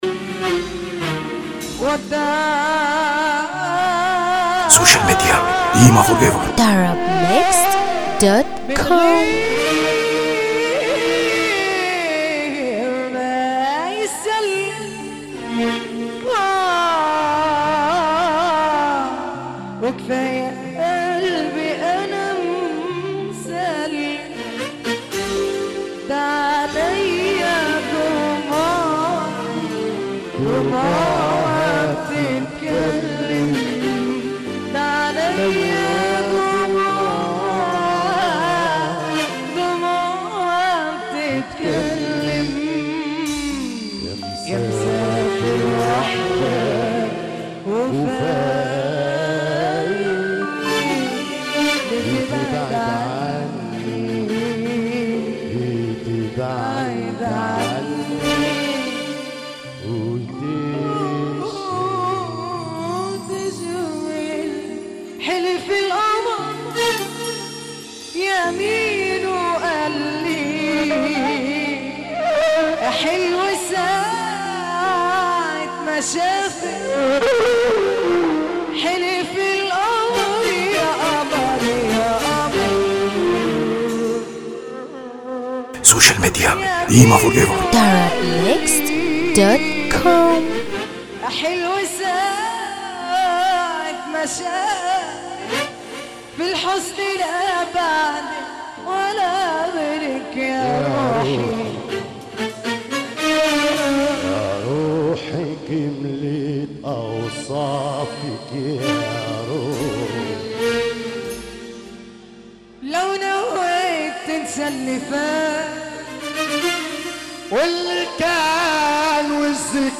دويتو
حزين